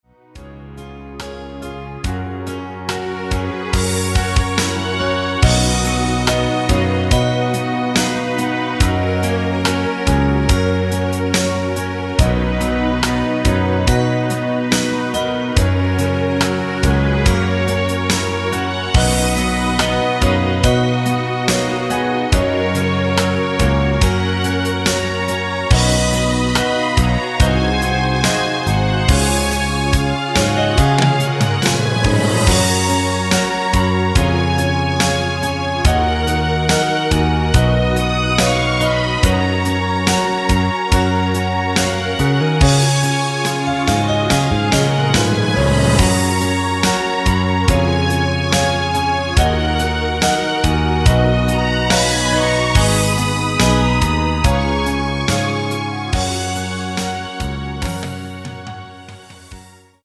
멜로디 MR 발매일 2000.05 여자키 멜로디 포함된 MR 입니다.(미리듣기 참조)
F#
앞부분30초, 뒷부분30초씩 편집해서 올려 드리고 있습니다.
중간에 음이 끈어지고 다시 나오는 이유는